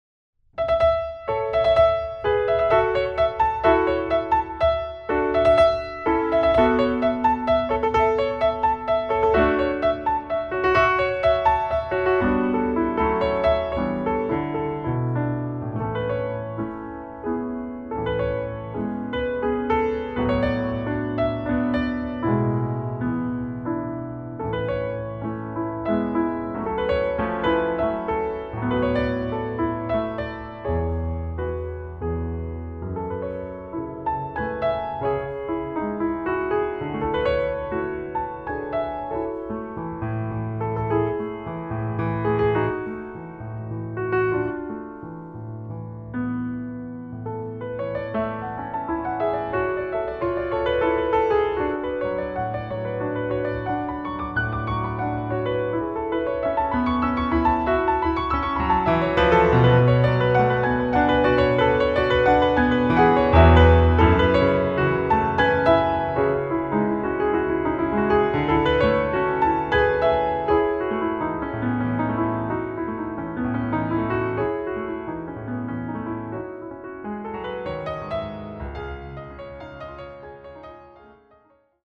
into a classical-pop-jazz atmosphere.
these piano pieces become the soundtrack of a video